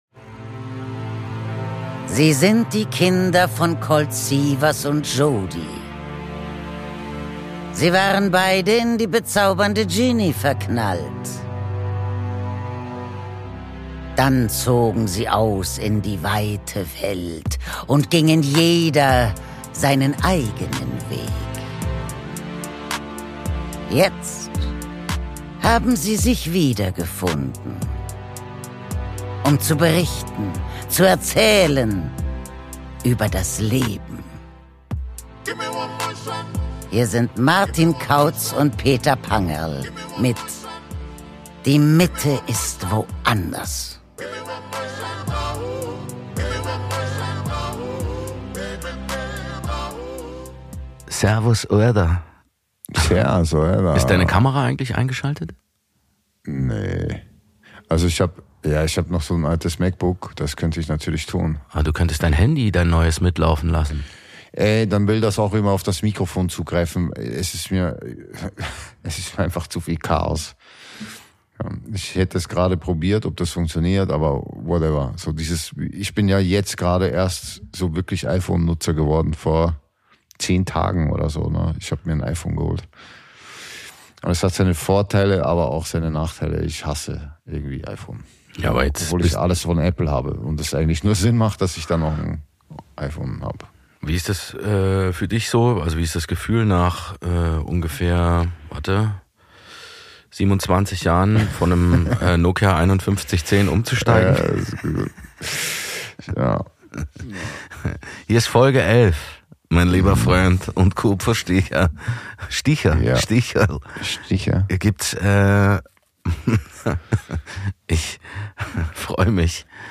Aus dem 25h Hotel im Wiener Museumsquartier